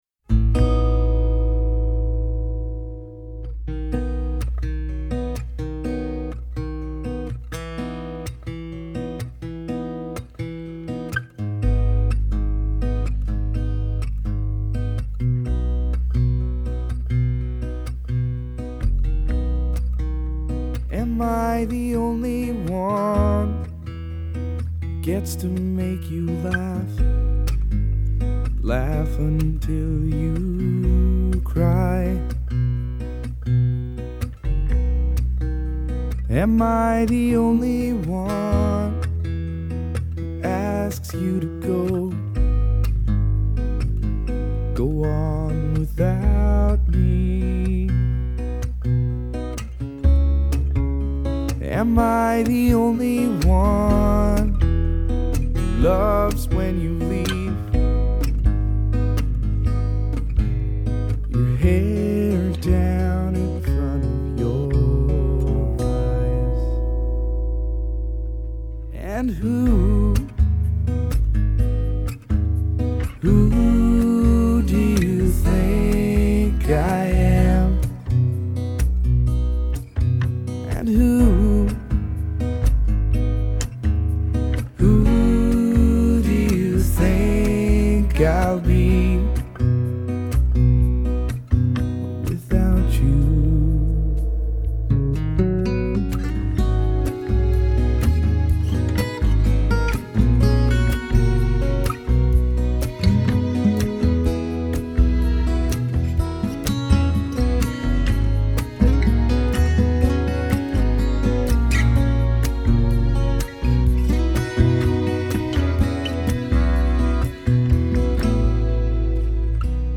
jazzy acoustic ballad